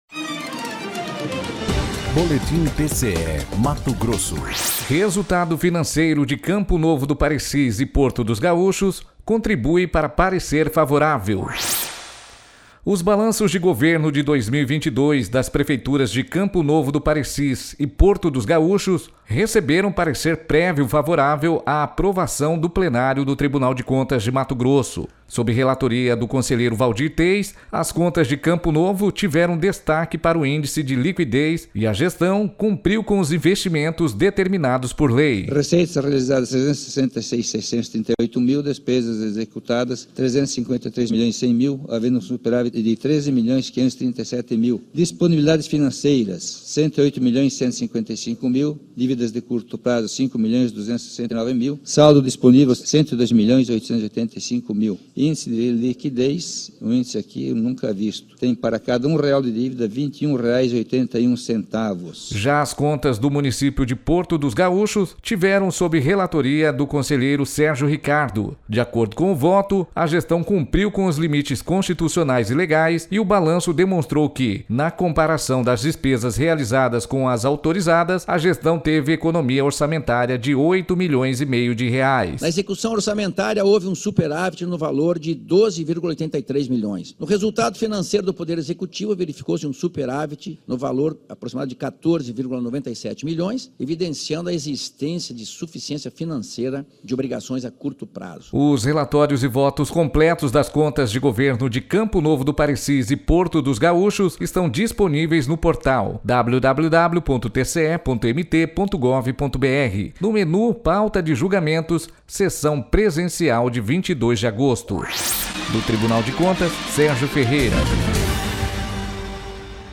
Sonora: Waldir Teis – conselheiro do TCE-MT
Sonora: Sérgio Ricardo – conselheiro do TCE-MT